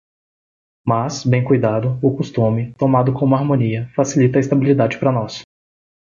Pronunciado como (IPA)
/toˈma.du/